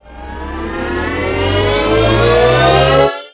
.瞄准.ogg